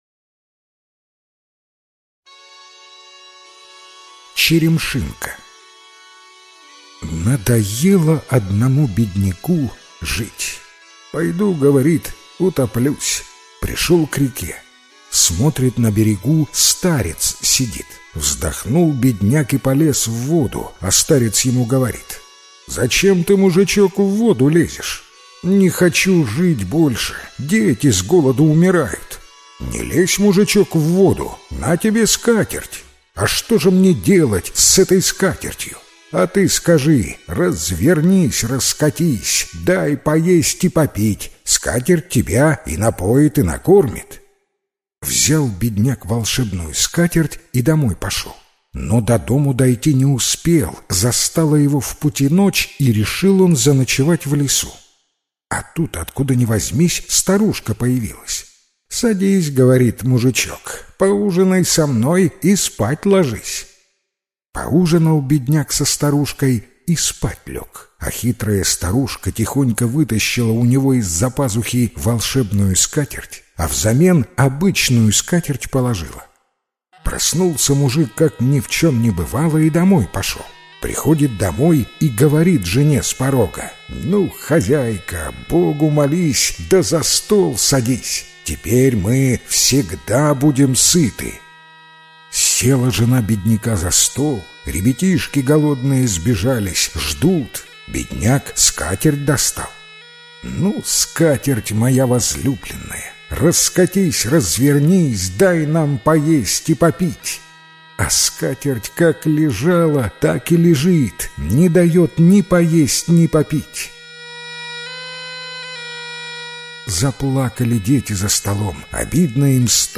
Черемшинка - белорусская аудиосказка - слушать онлайн